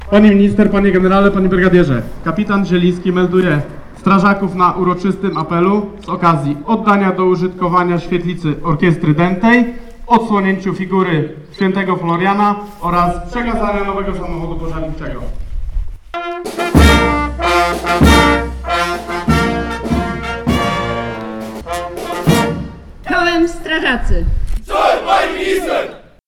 Jako pierwszy głos zabrał burmistrz Kcyni Piotr Hemmerling, który powitał zaproszonych gości. Następnie w swoim wystąpieniu zaznaczył, że ważnym dla gminy Kcynia są takie zakupy jak ten wóz strażacki, który zdecydowanie poprawi bezpieczeństwo.